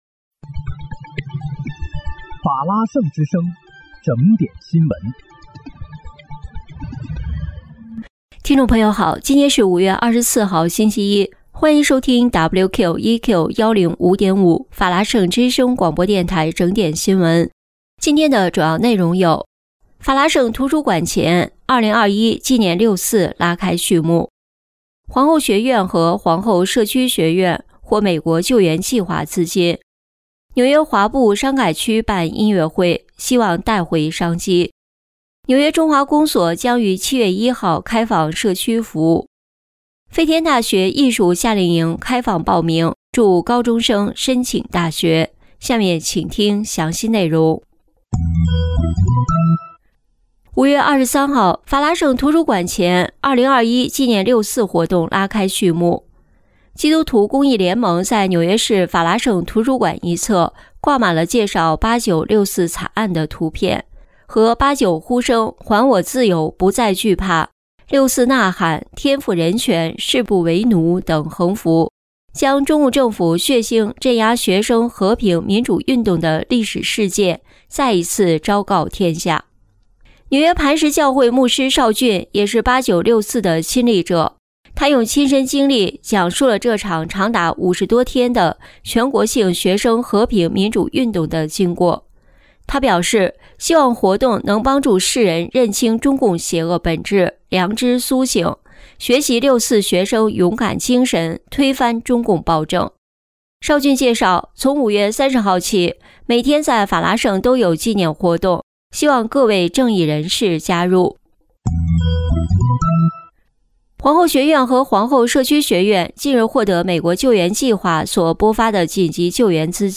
5月24日（星期一）纽约整点新闻
听众朋友您好！今天是5月24号，星期一，欢迎收听WQEQ105.5法拉盛之声广播电台整点新闻。